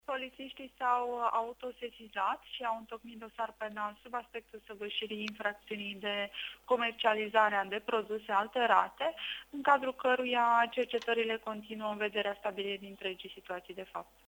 Dosar penal în cazul shaormeriei din Iași care a imbolnavit zeci de persoane. Declarația